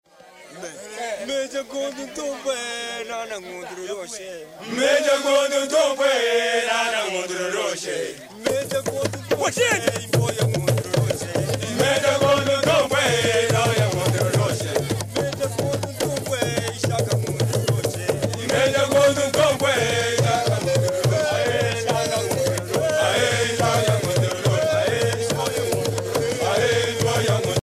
gestuel : danse
Pièce musicale éditée